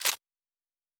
pgs/Assets/Audio/Sci-Fi Sounds/Weapons/Weapon 15 Foley 2.wav at master
Weapon 15 Foley 2.wav